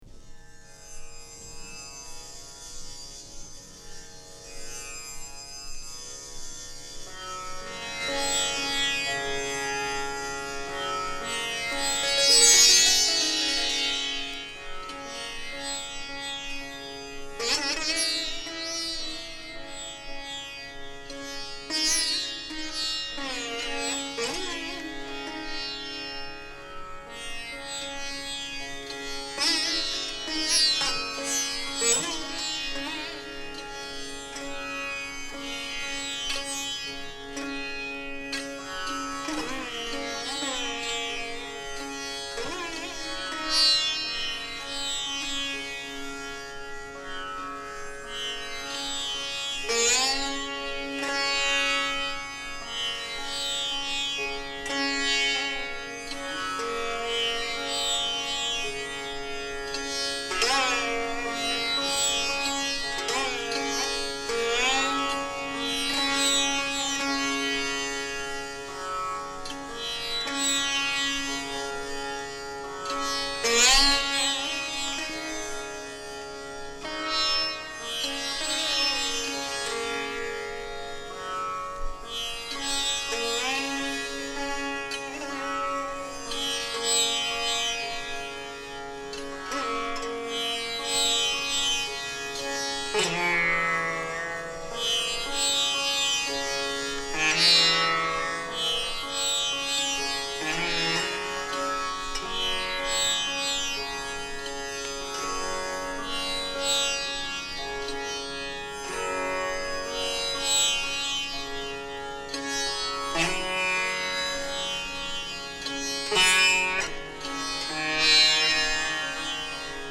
＊B面頭に傷有り。プチノイズ入ります。